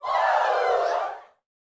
SHOUTS19.wav